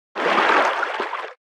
Sfx_creature_seamonkeybaby_swim_slow_02.ogg